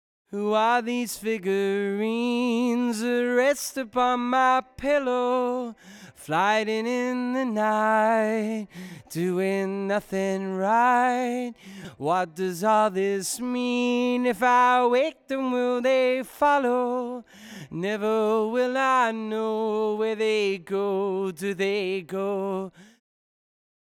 Whilst The first sample is unprocessed, the other samples are compressed by 1:2, and 1:4 respectively.
compressor_vocal_unprocessed.wav